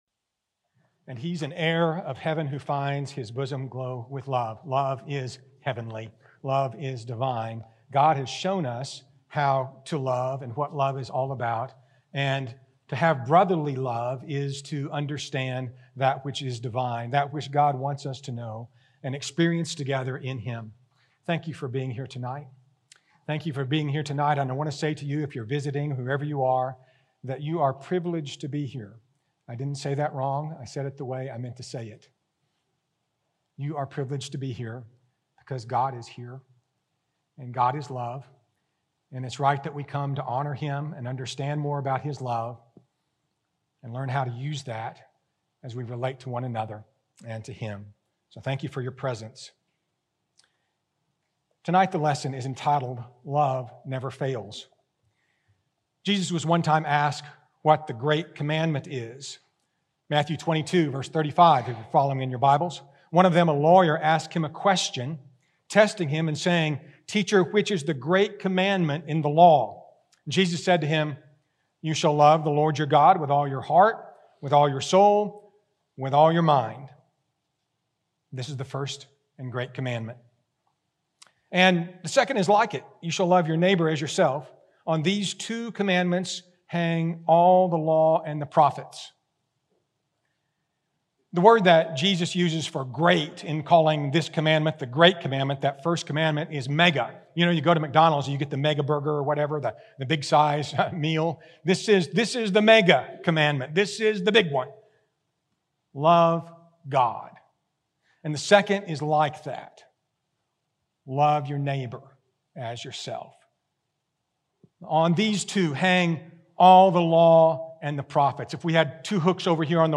For week two of our Spring Series, in which we have a visiting speaker every Sunday in April